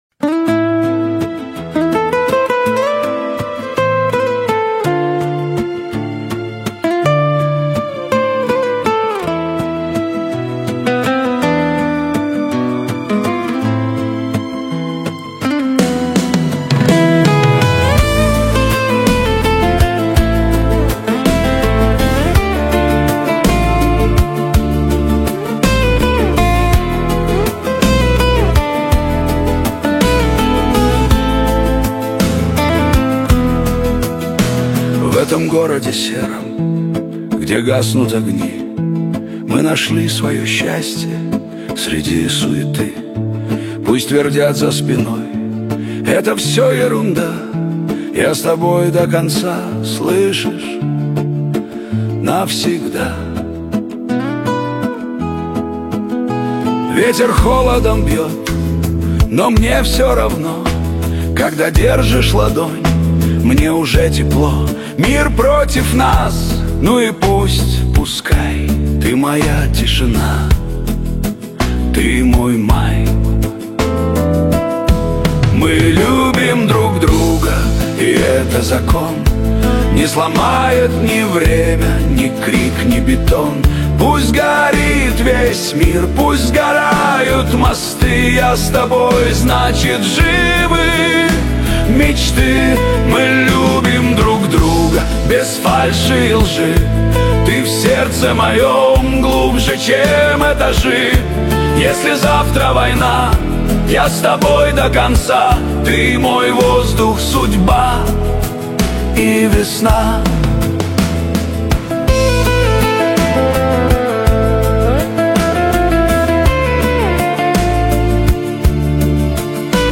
Душевная песня о настоящей любви